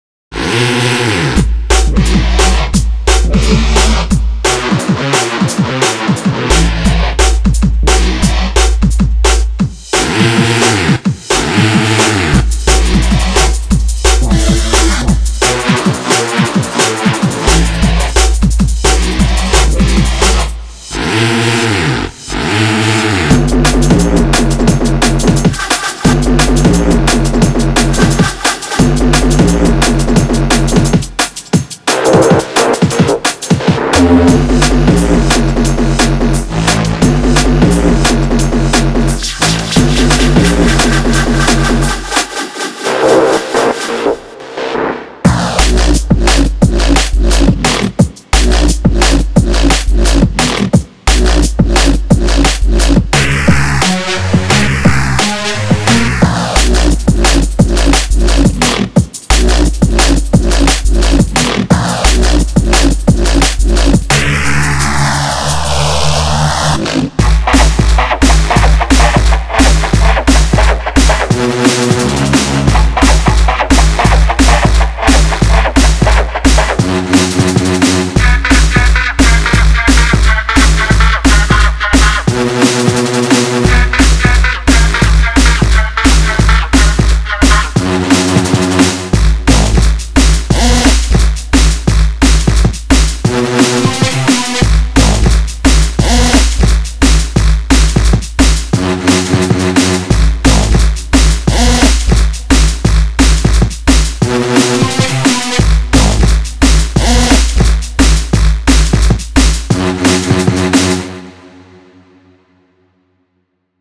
32 Breakbeats
16 Reese Bass